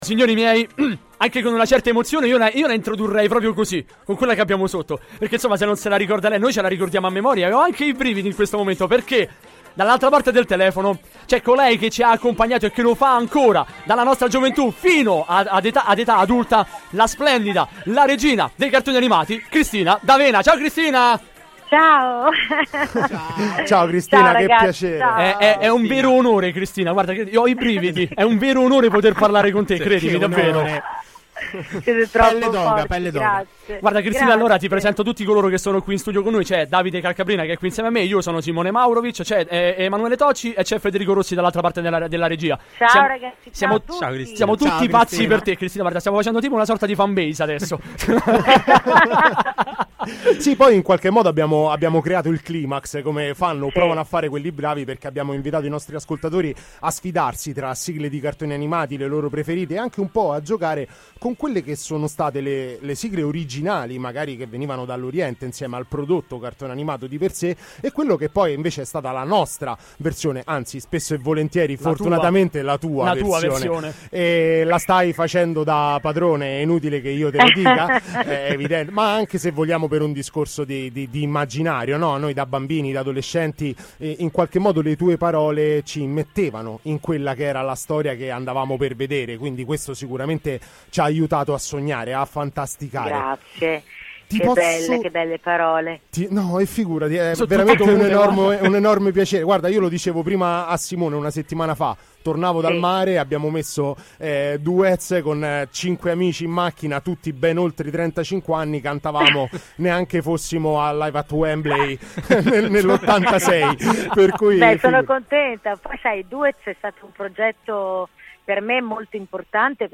Intervista a Cristina D’Avena ospite di Stregati Dalla Rete | Radio Città Aperta